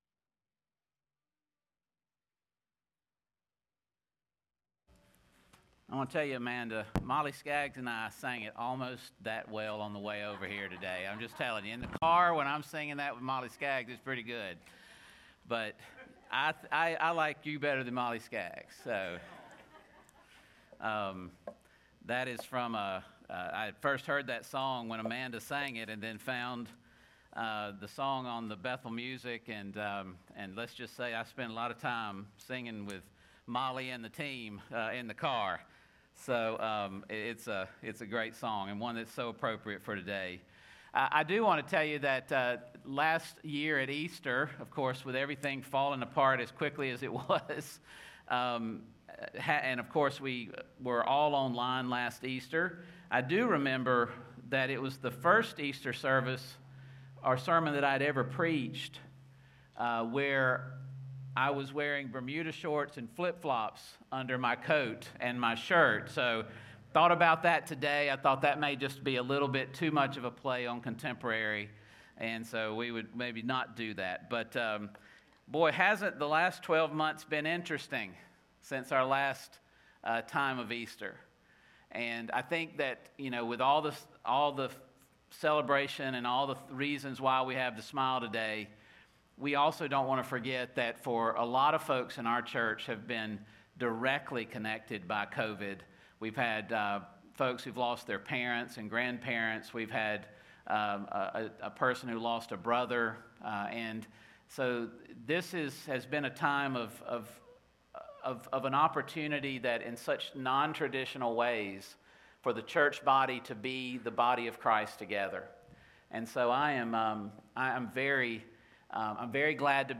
On Saturday night of Holy Week
where the worship music is mixed in with her sermon.